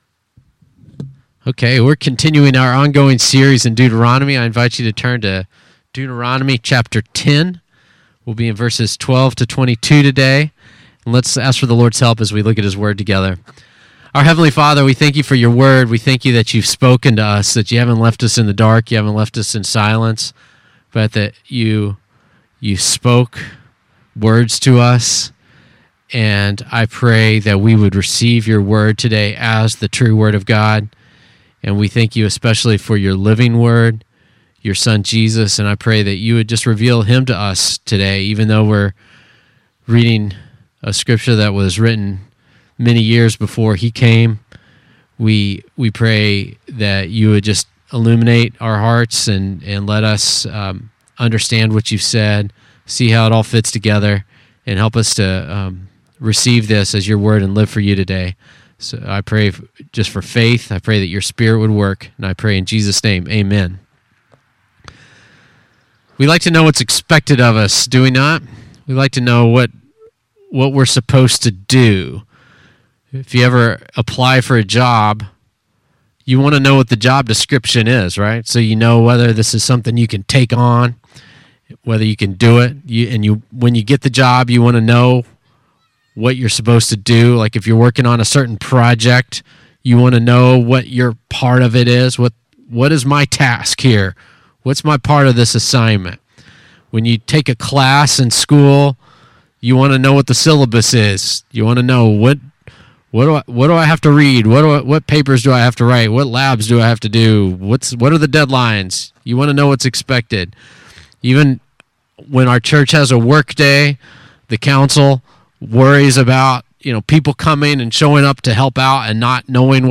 Listen to sermons by our pastor on various topics.